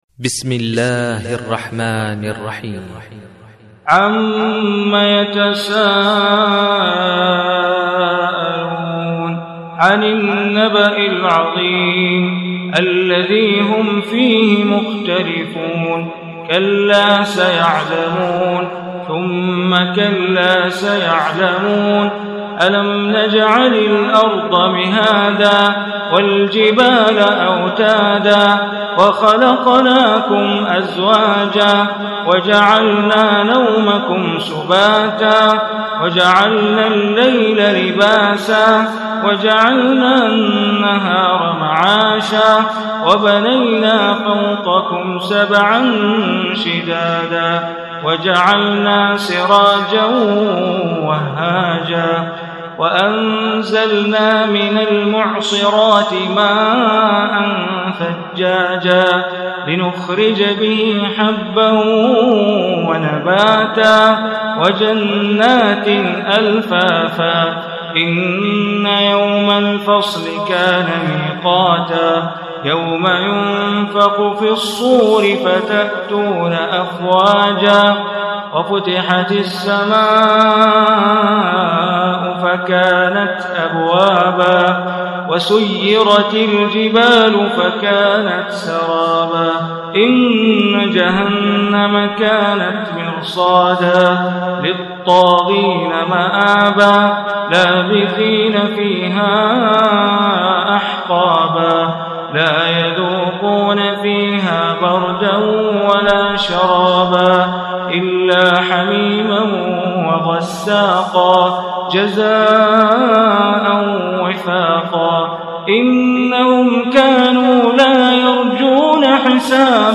Surah Naba MP3 Recitation by Sheikh Bandar Baleela
Surah Naba, is 78 surah of Quran. Listen online or download mp3 tilawat / recitation in Arabic in the beautiful voice of Sheikh Bandar Baleela.